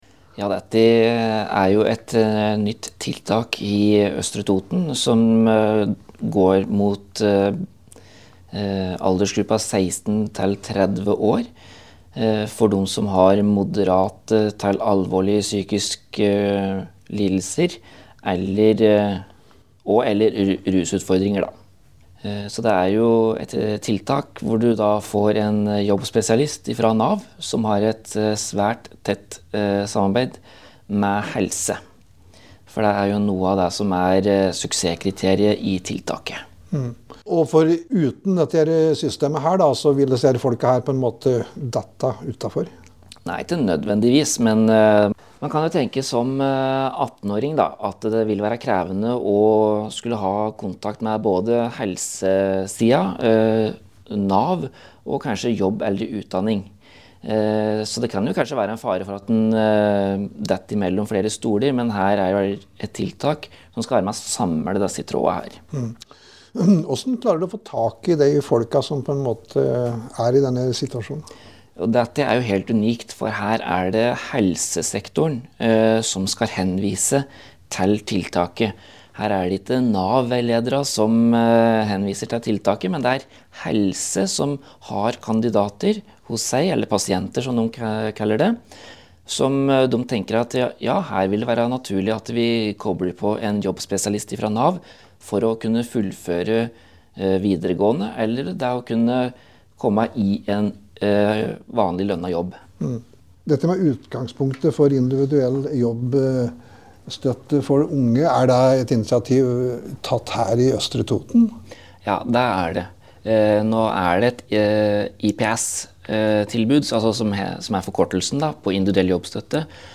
I den forbindelse har vår reporter tatt en prat med jobbspesialist